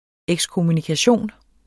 Udtale [ εgskomunikaˈɕoˀn ]